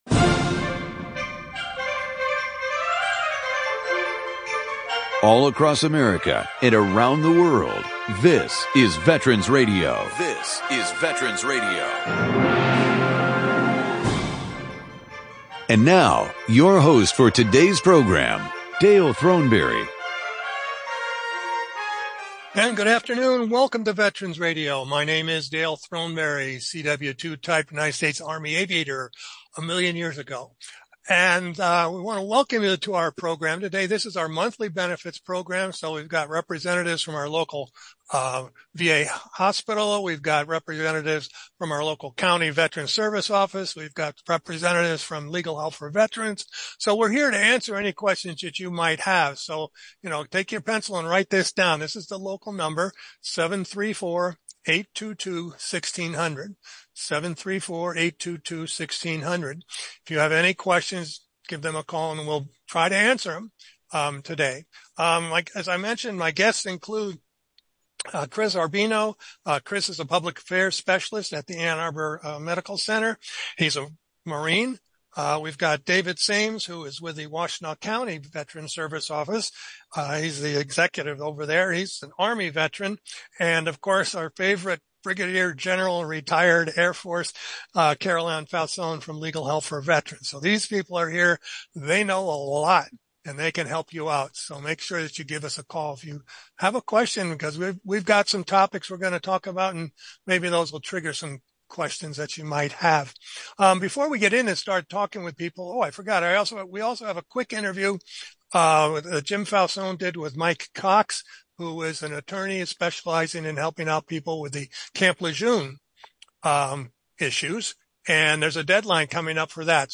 Today is our monthly veterans benefits hour with our panel of benefits experts! Call in to the live show with your benefits questions.